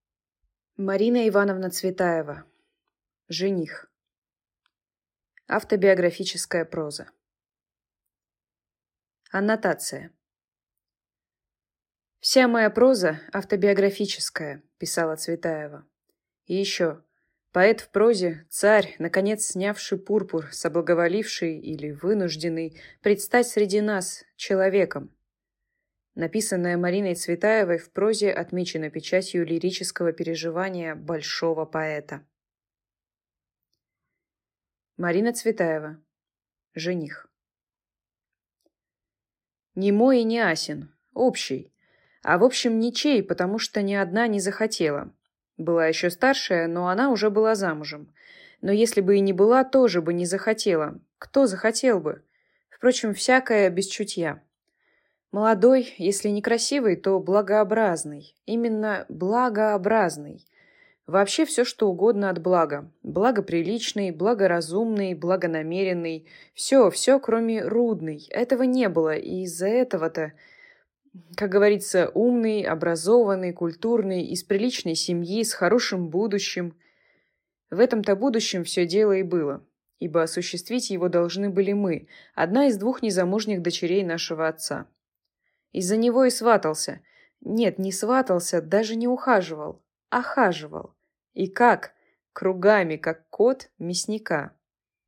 Аудиокнига Жених | Библиотека аудиокниг